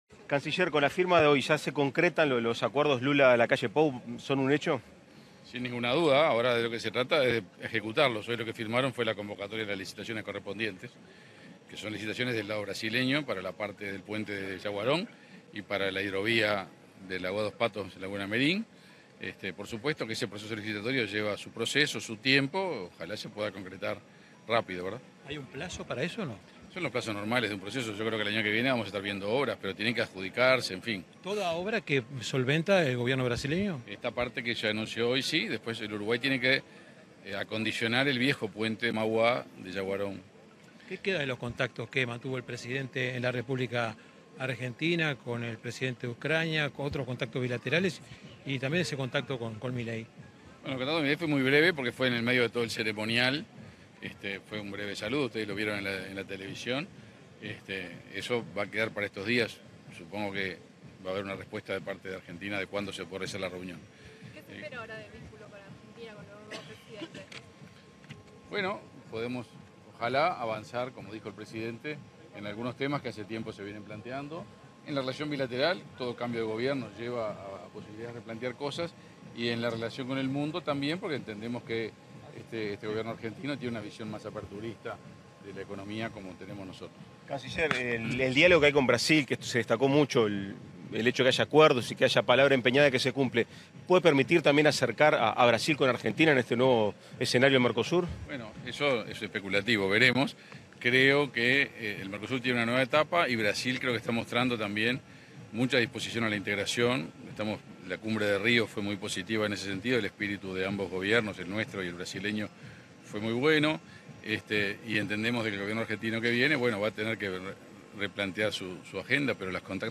Declaraciones a la prensa del ministro de Relaciones Exteriores, Omar Paganini
Declaraciones a la prensa del ministro de Relaciones Exteriores, Omar Paganini 12/12/2023 Compartir Facebook Twitter Copiar enlace WhatsApp LinkedIn En el marco de la inauguración del aeropuerto binacional de Rivera, este 11 de diciembre, el ministro de Relaciones Exteriores, Omar Paganini, dialogó con la prensa.